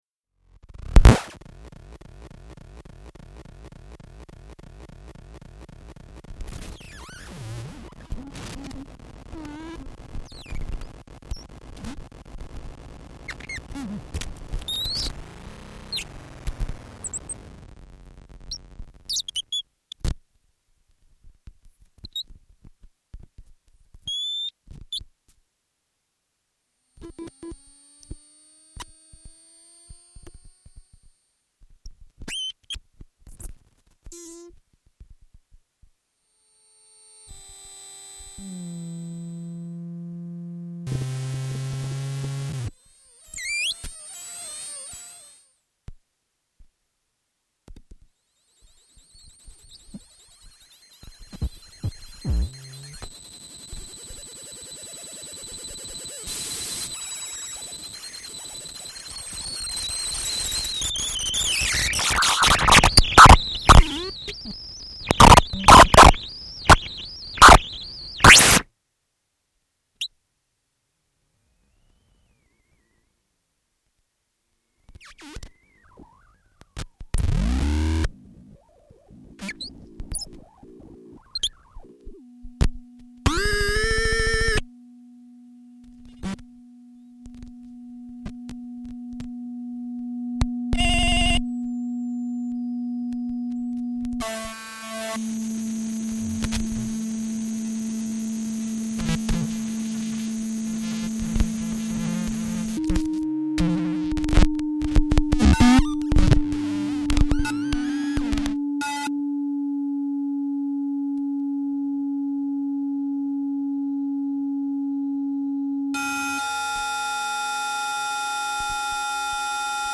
electronics